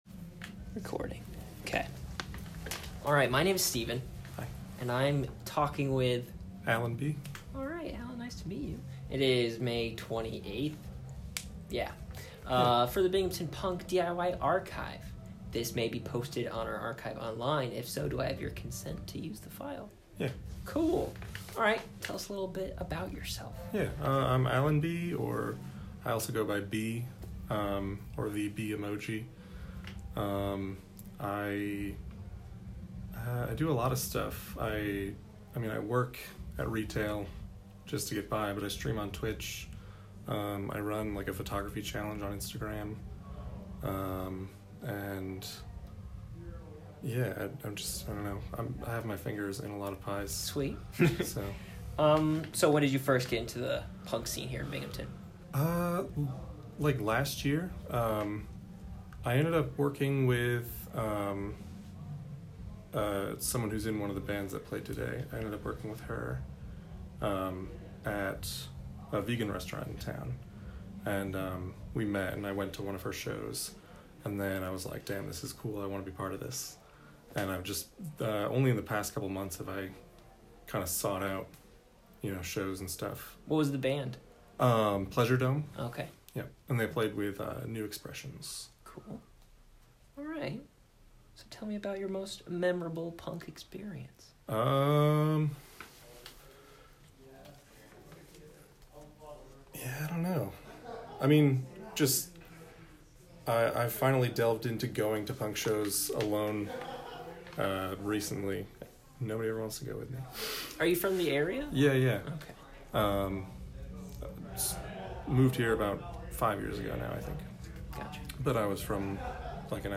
Oral Histories